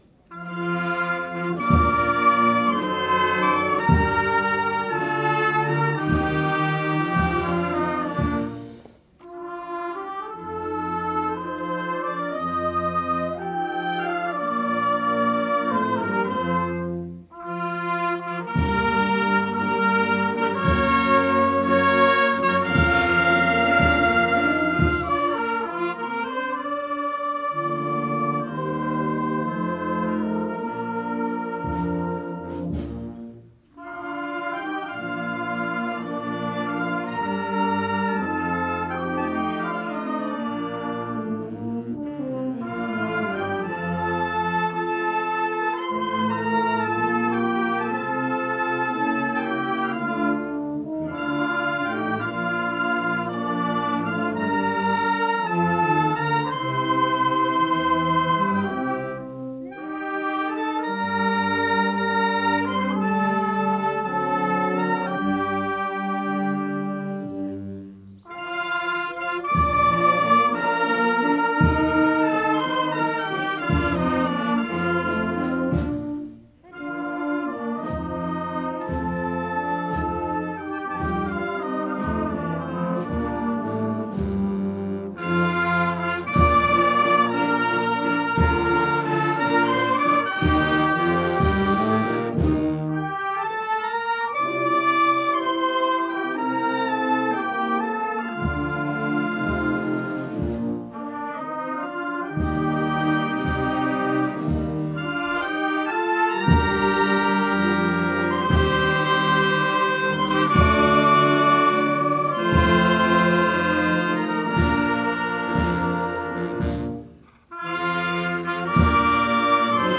Inno Ufficiale a Maria SS. ad Rupes – Banda Musicale di Castel Sant’Elia